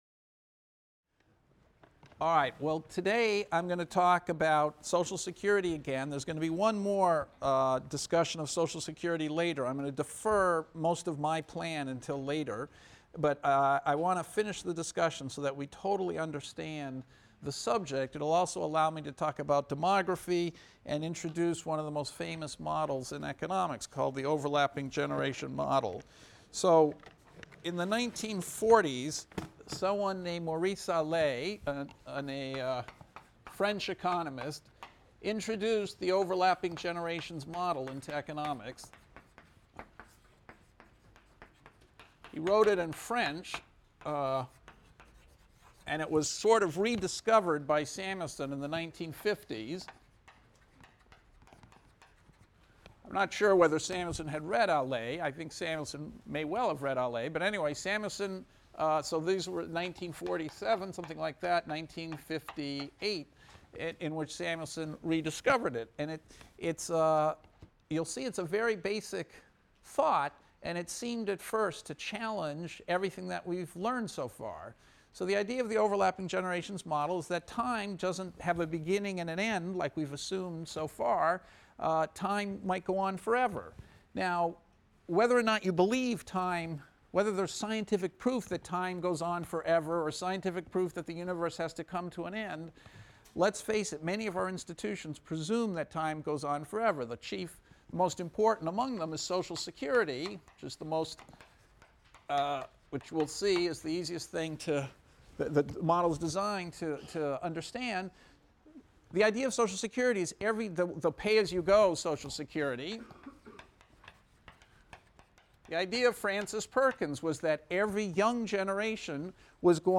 ECON 251 - Lecture 12 - Overlapping Generations Models of the Economy | Open Yale Courses